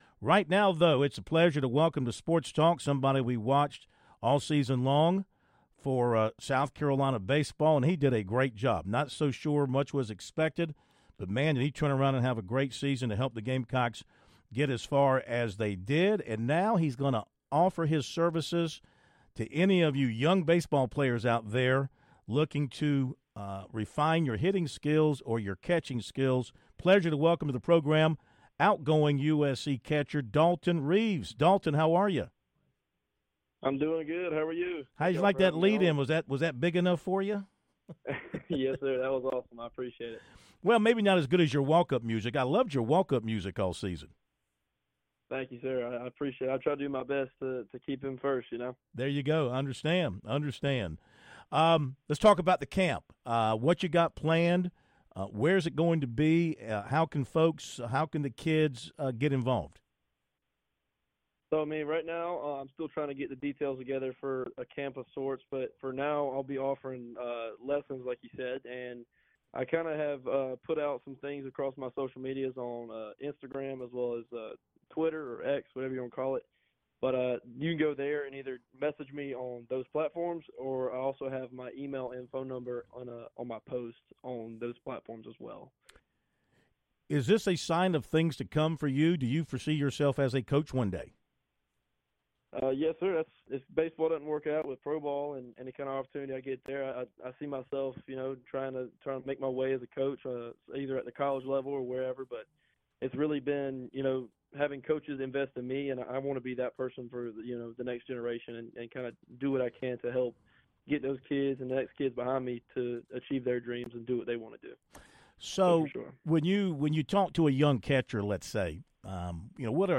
SportsTalk interview